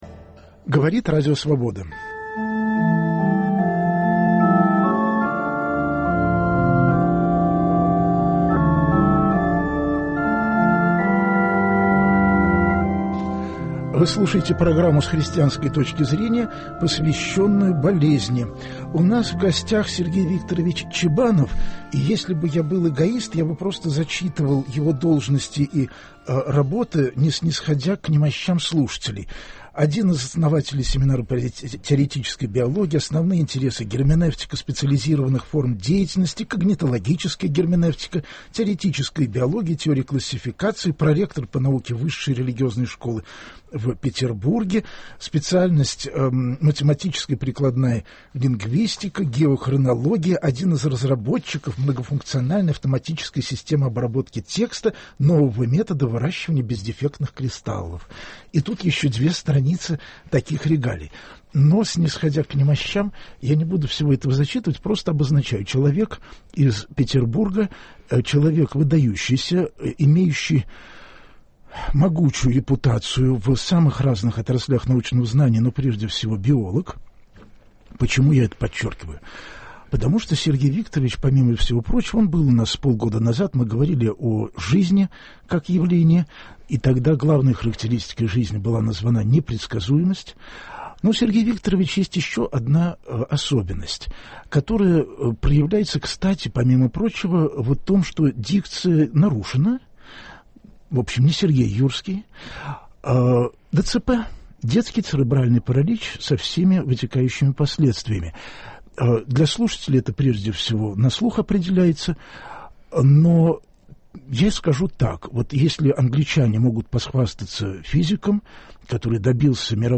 Гость программы - учёный-биолог из Петербурга